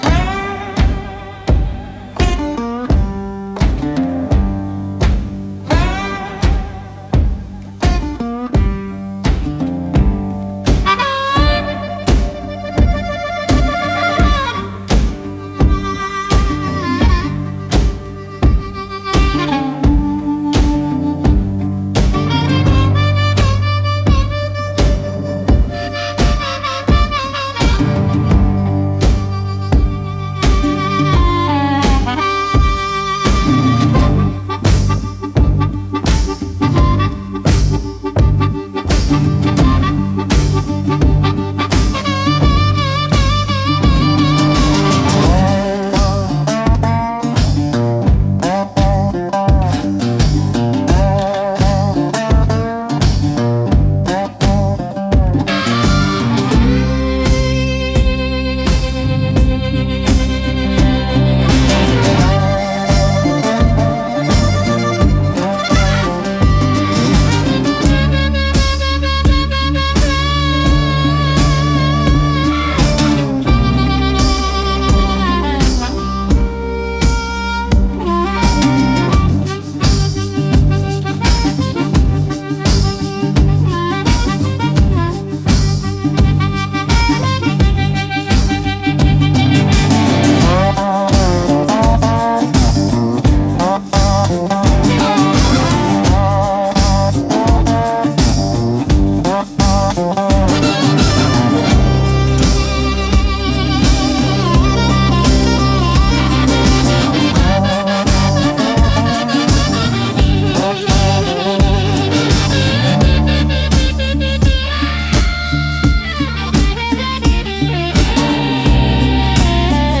Instrumental - Coal Country Confessional 2.51